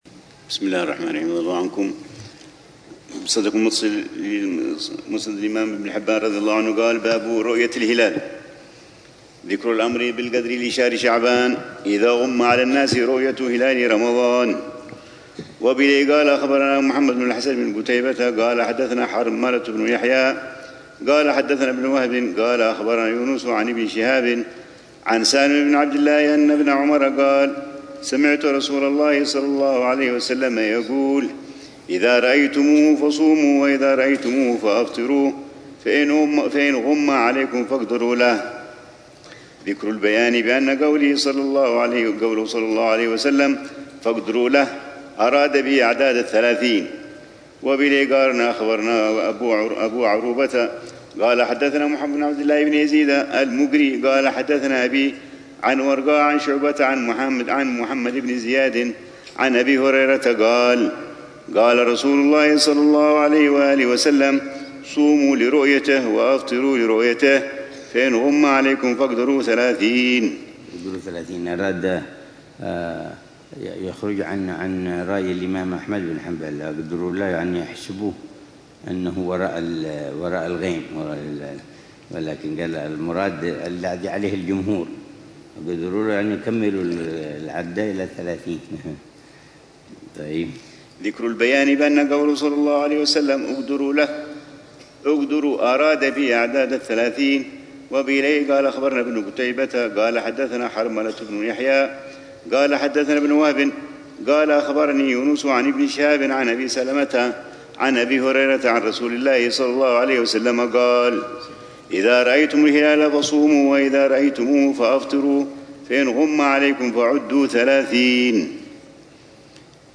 الروحة الرمضانية التاسعة بدار المصطفى لعام 1446هـ ، وتتضمن شرح الحبيب العلامة عمر بن محمد بن حفيظ لكتاب الصيام من صحيح ابن حبان، وكتاب الصيام